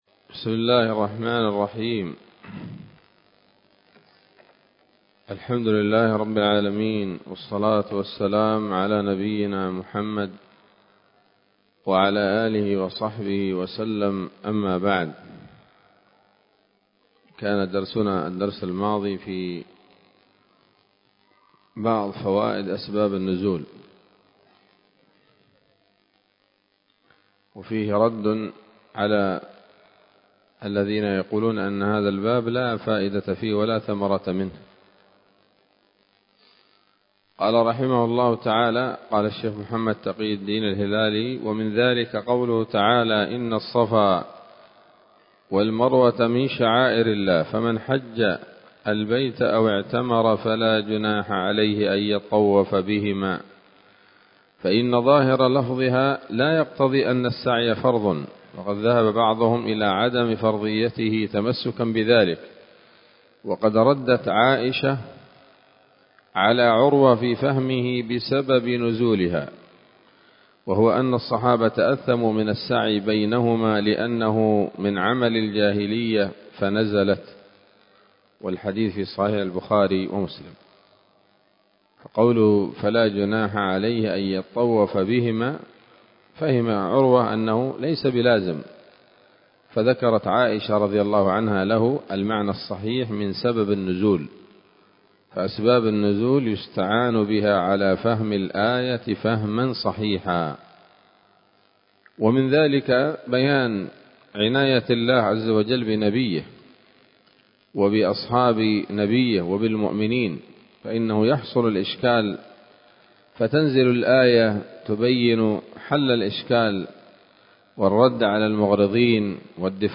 الدرس السادس عشر من كتاب نبذة من علوم القرآن لـ محمد تقي الدين الهلالي رحمه الله